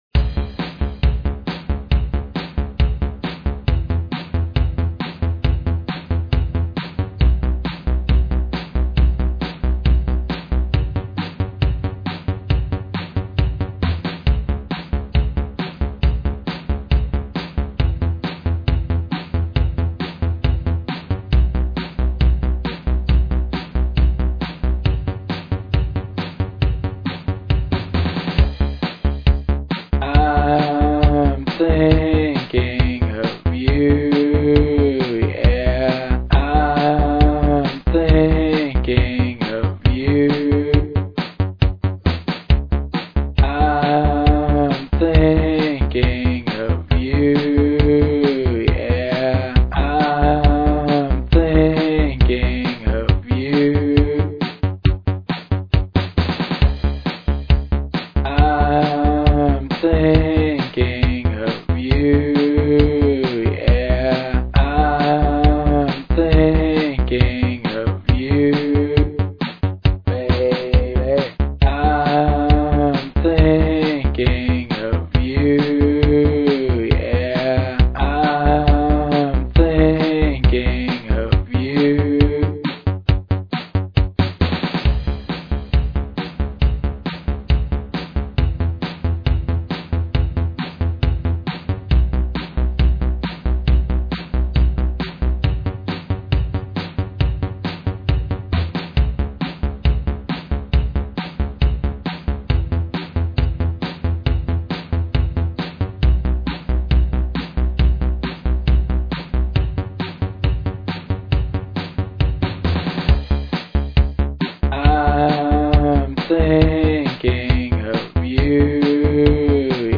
Pop/Dance
repeated vocal chorus over a bass line